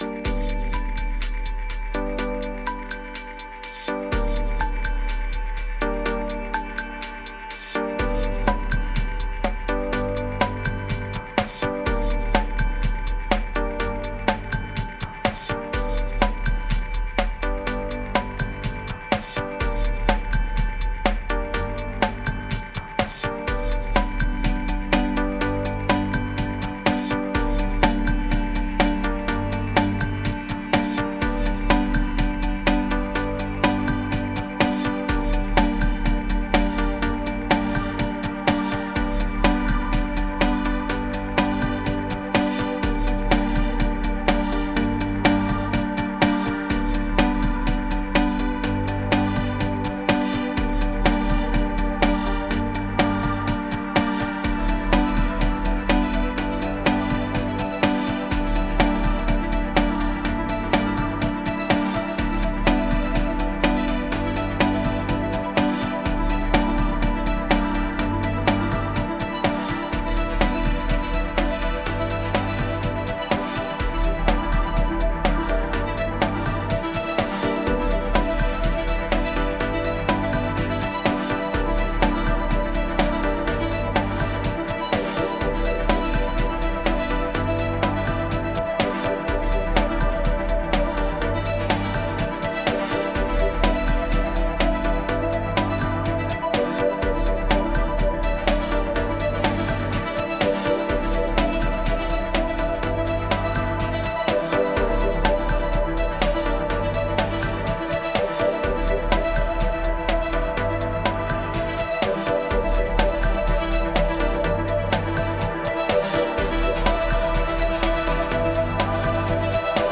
Electro, Drummachine, Hypnotica